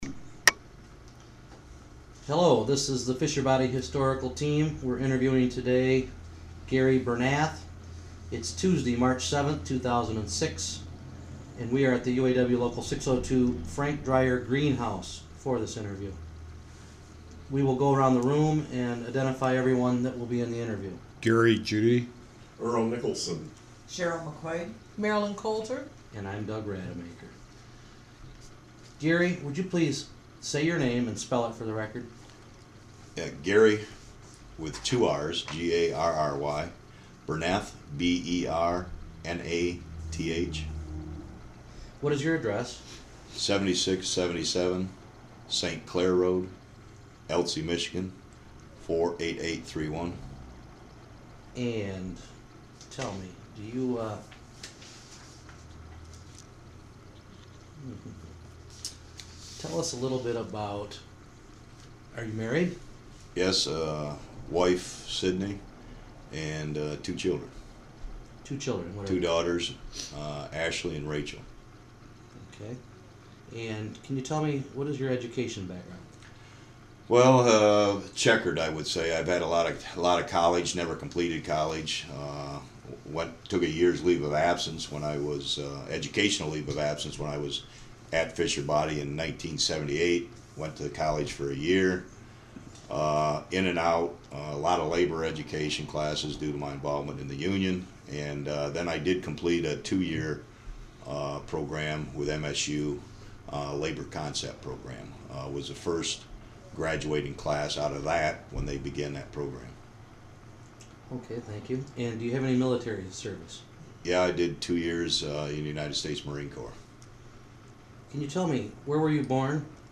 United Auto Workers Local 602/General Motors Oral History Project